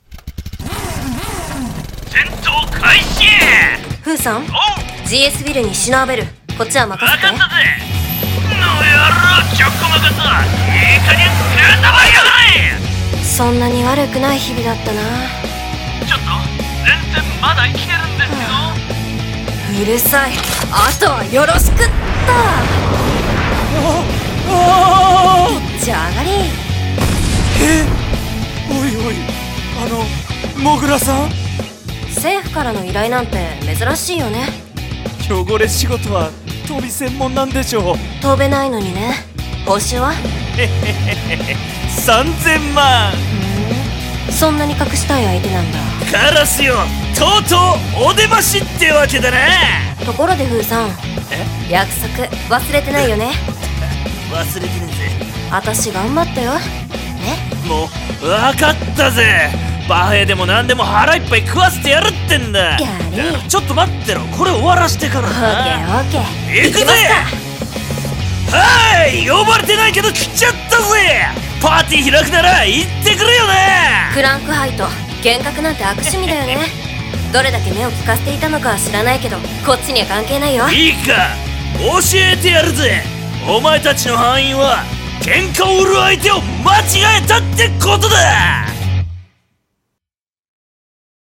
】【相棒系声劇】GREIFEN!!